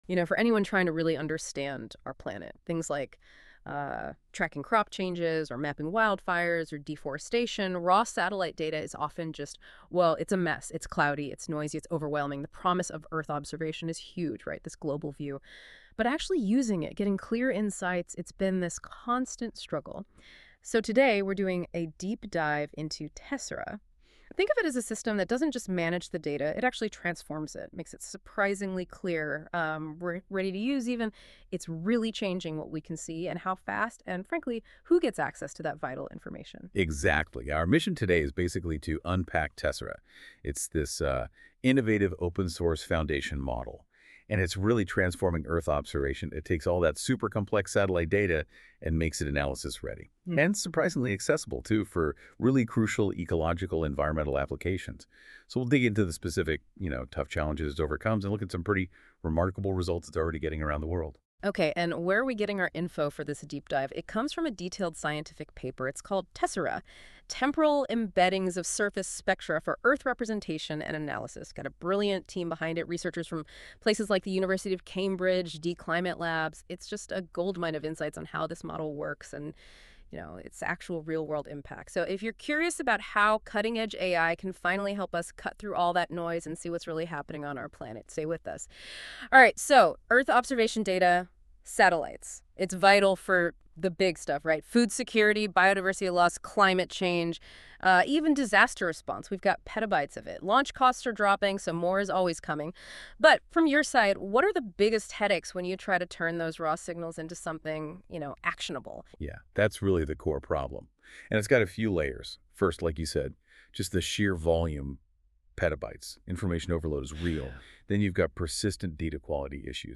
Read the paper or listen to this NotebookLM-generated podcast explaining it.